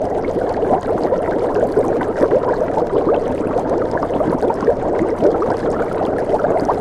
cauldron.ogg.mp3